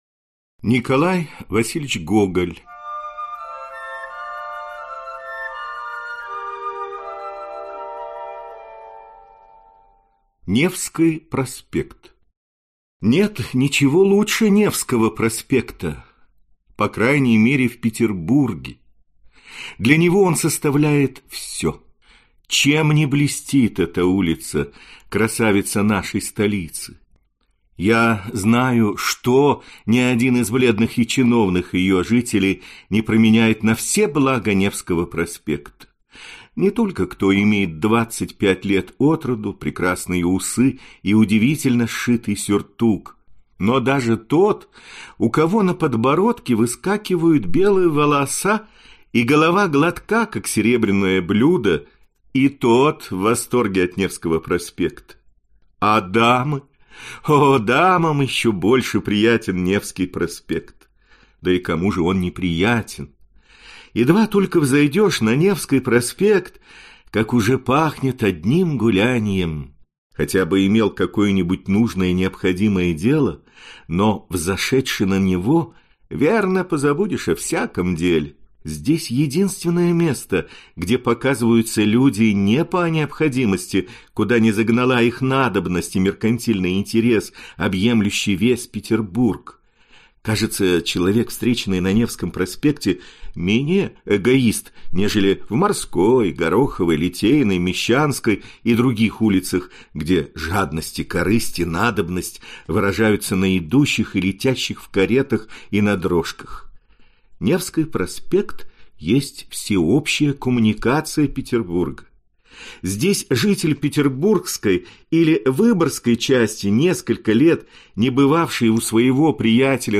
Аудиокнига Невский проспект | Библиотека аудиокниг
Прослушать и бесплатно скачать фрагмент аудиокниги